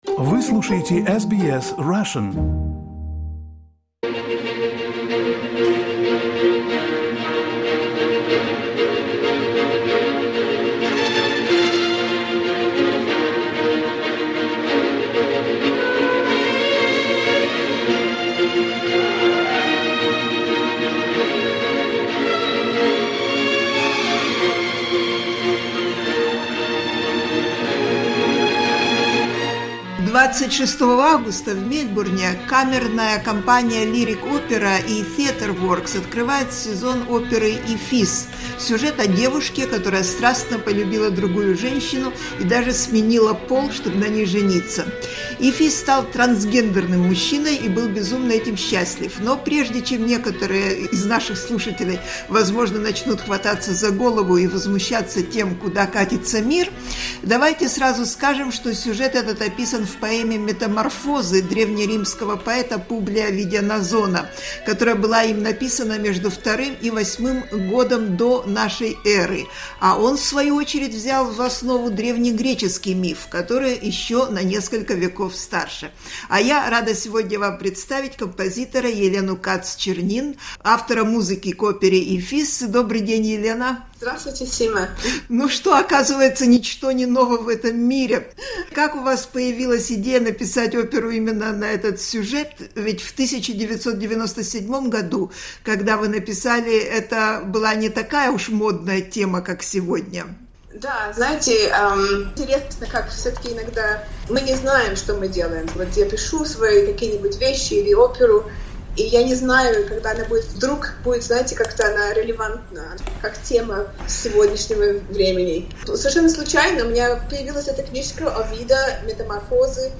Interview with Elena Katz-Chernin, who came to Australia back in 1975, and has become one of the most sought-after Australian composers.